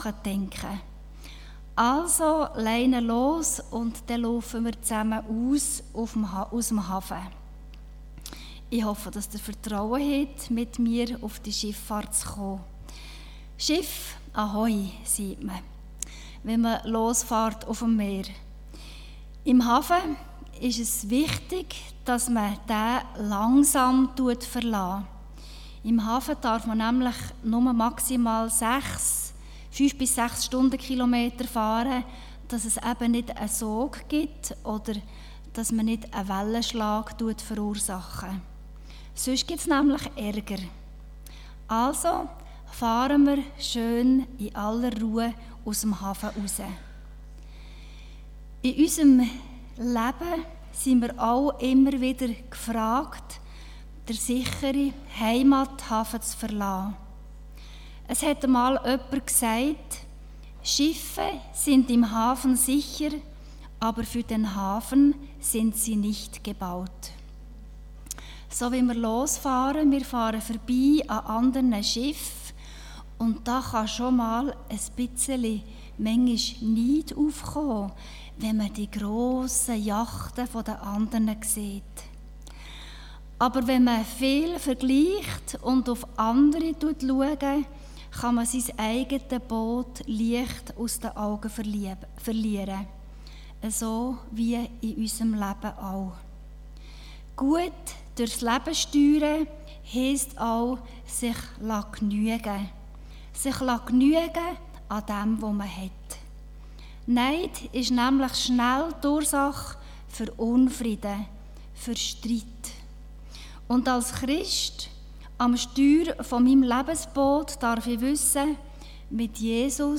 Von Serien: "Diverse Predigten"